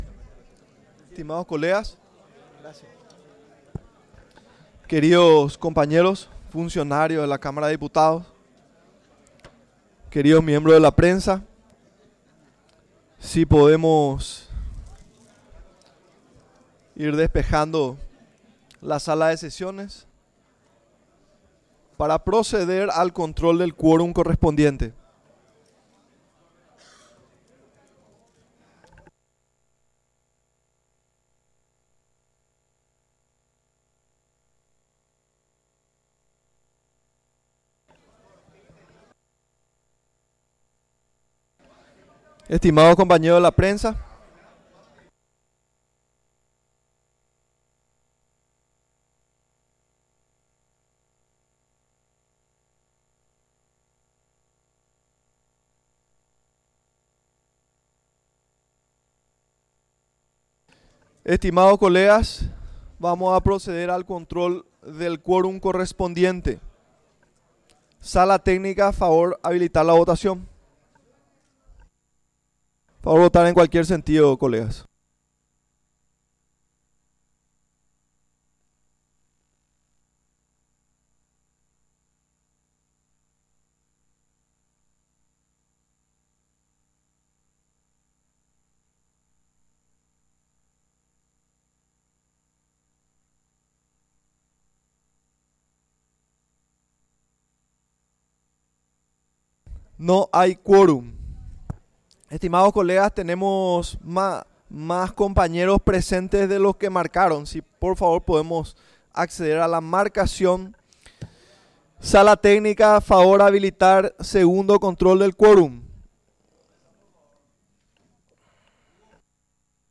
Sesión Ordinaria, 17 de junio de 2025